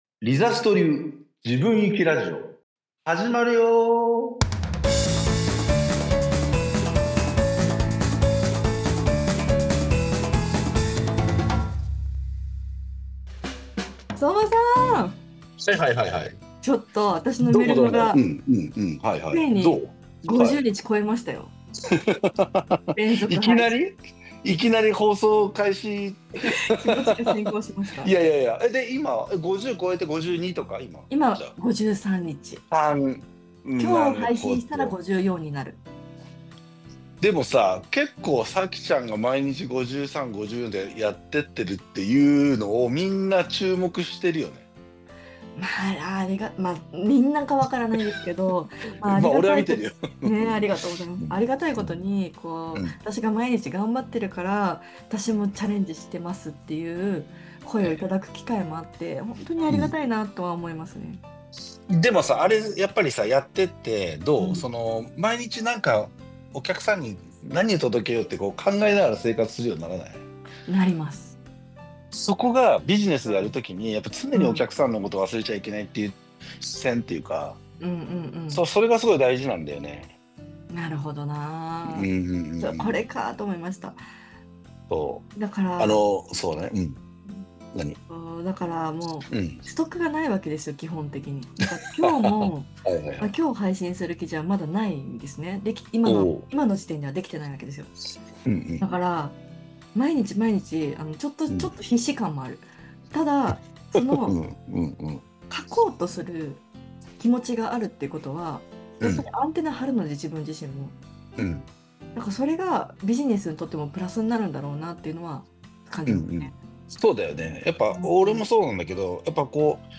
今回のラジオでは